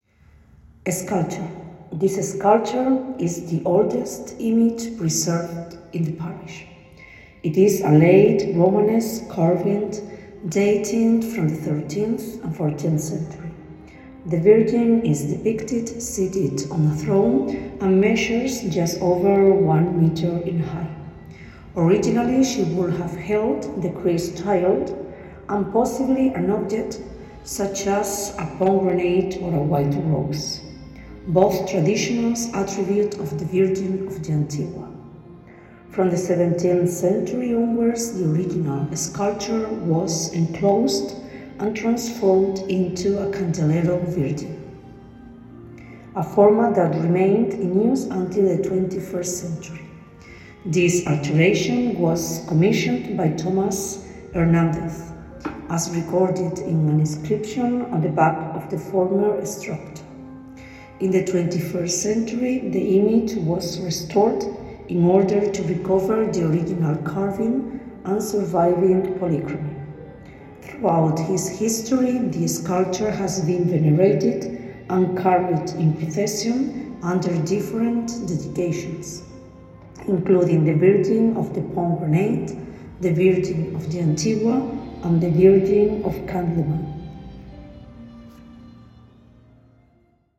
Bloque Principal de la Entidad Ayuntamiento de Segura de León .escultura2 Escultura / Sculpture usted está en Capilla del Rosario / Chapel of the Rosary » Escultura / Sculpture Para mejor uso y disfrute colóquese los auriculares y prueba esta experiencia de sonido envolvente con tecnología 8D.